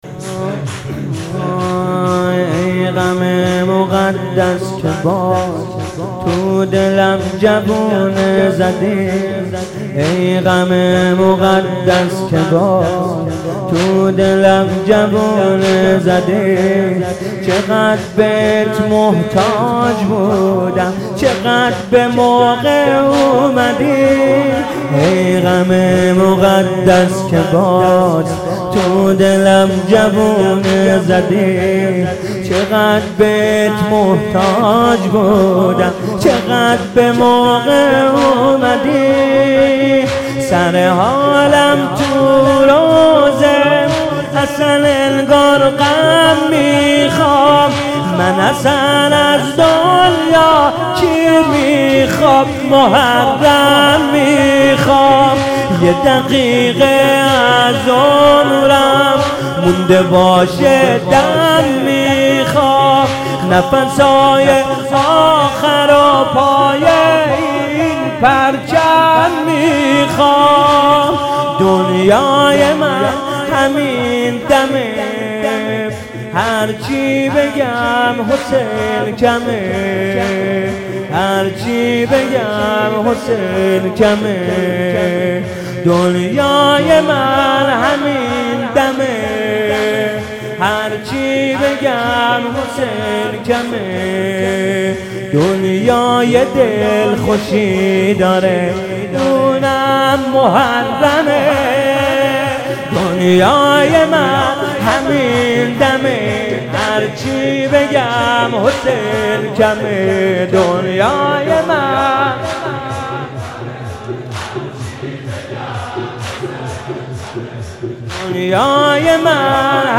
ای غم مقدس مداحی جدید حسین طاهری شب دوم محرم 1400